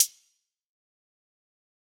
Closed Hats
Metro Hats [Dark Hats].wav